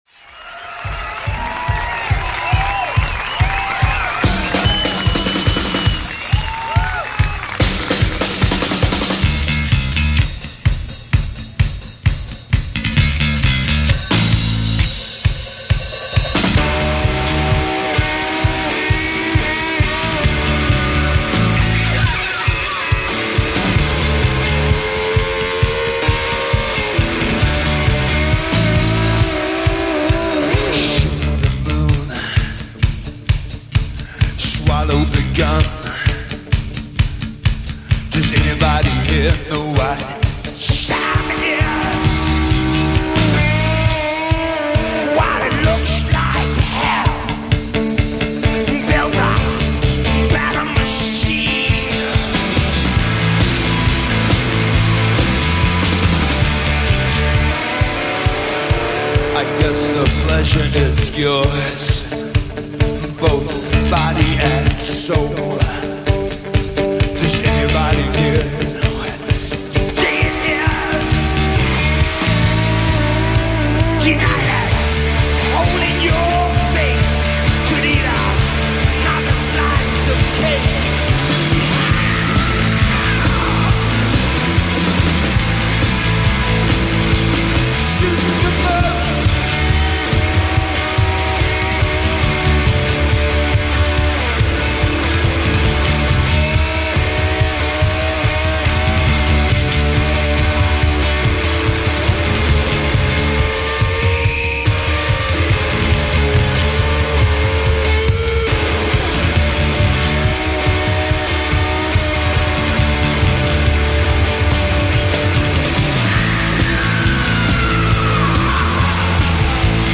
recorded in concert from Manchester in 1990 by the BBC.
Indie
co-founder and vocalist
heavy Indie/alternative presence